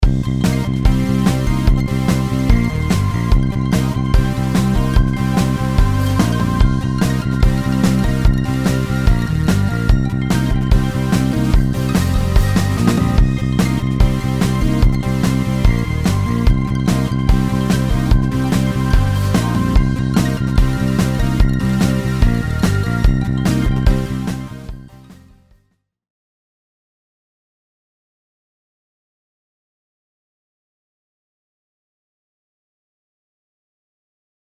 デモ音源におけるパート内訳は、Omnisphereを3トラック使用して作成したシンセパッドのパート、Trilianによるエレクトリックベースのパートに加えて、ドラムパートにToontrackのSuperior Drummer 3、ディストーションギター風のシンセリード音色パートにReveal SoundのSpireという他社製2製品を敢えて加えた構成です。
SpireとSuperior Drummerにインサーションとして使用しているFX-Omnisphereをオフにした状態のサンプル音源はこちら。
FX-Omnisphere_Off.mp3